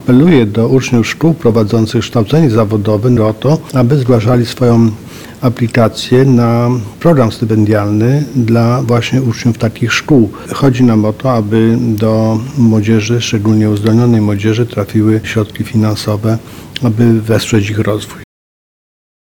Marszałek Województwa Mazowieckiego Adam Struzik zachęca do składania wniosków.